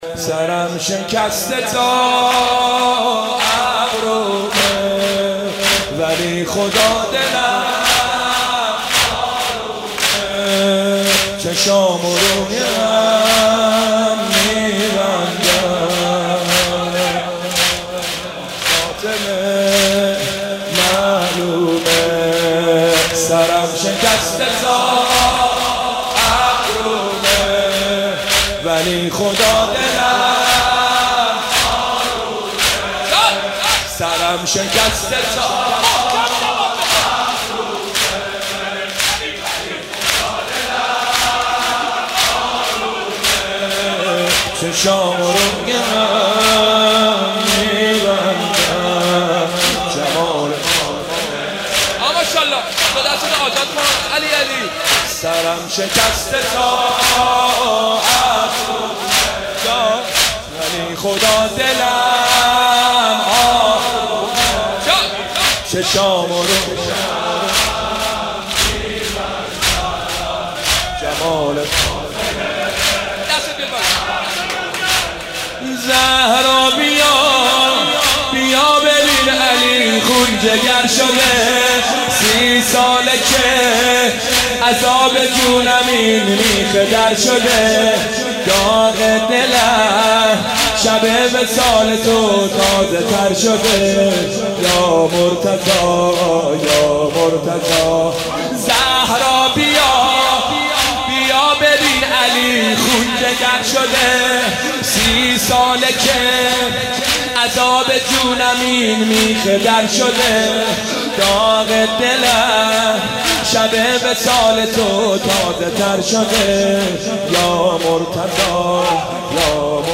شب 19 رمضان
مداحی نوحه